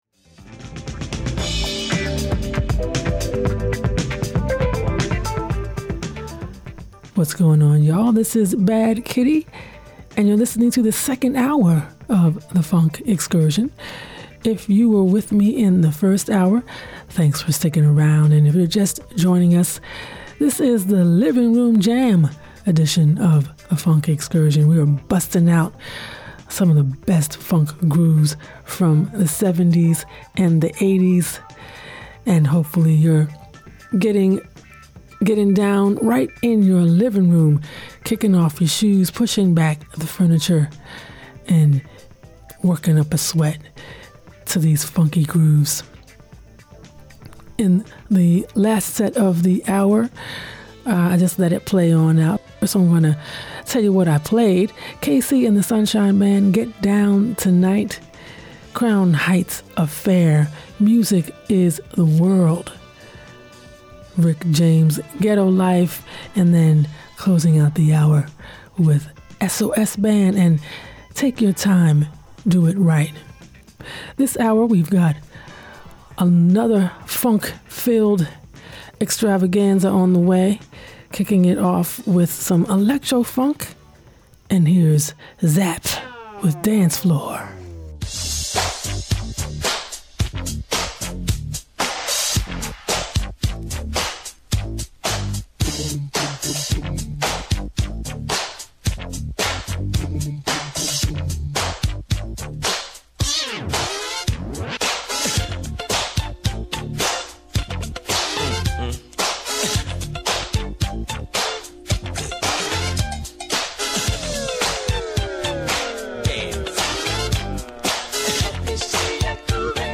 extended sets of pure Funk dance jams
disco funk
electro-funk and house